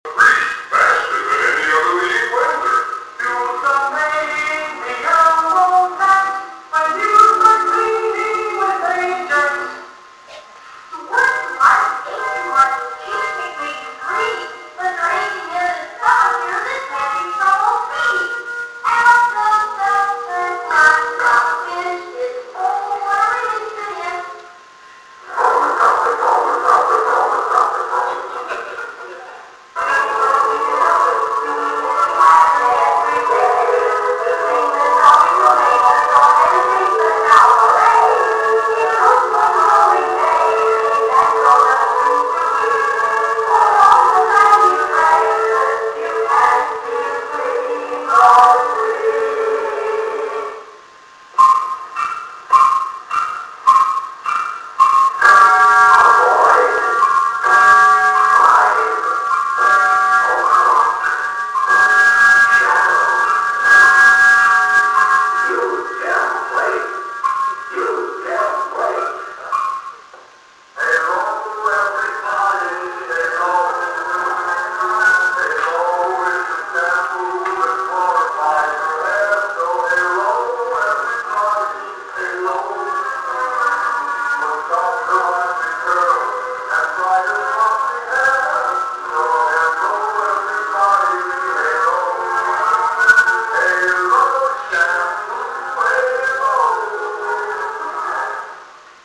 A Nostalgic & entertaining evening of recorded cuts from some of the most remembered programming from the Golden Age of Radio.